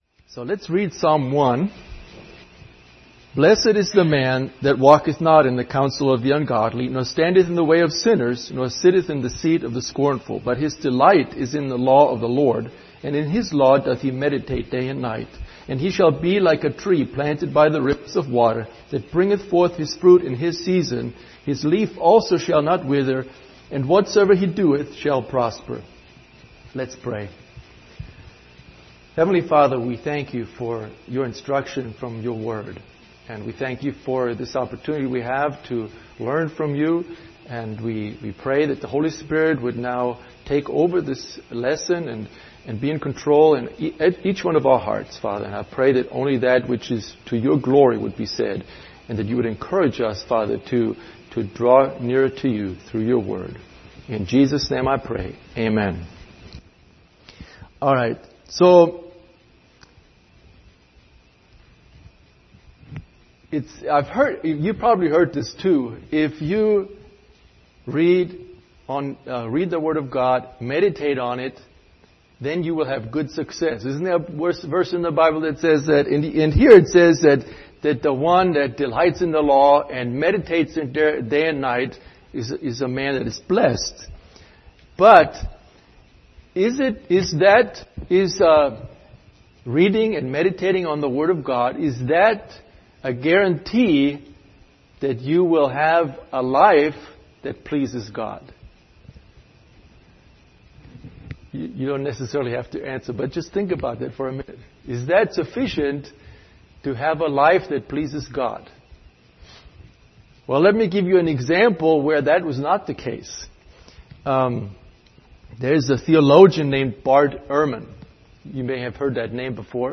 Psalm 1 Service Type: Wednesday Evening Topics